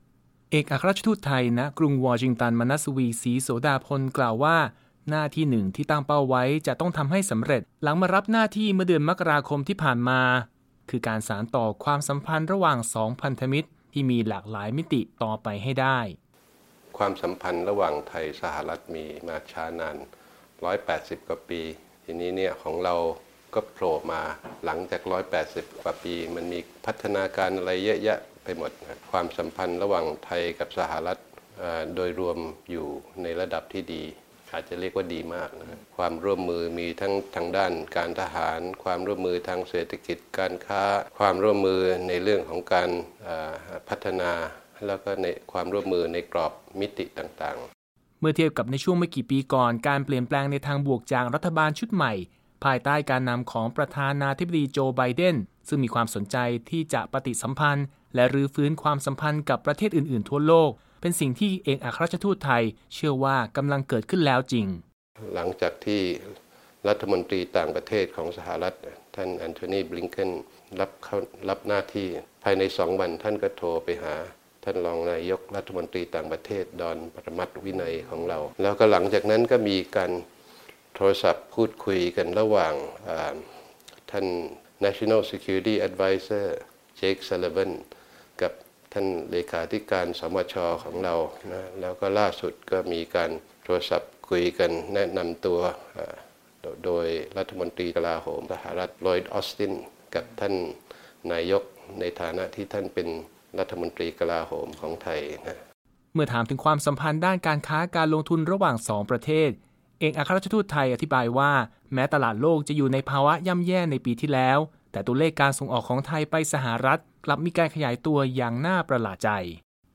Thai Ambassador Interview on Thai-US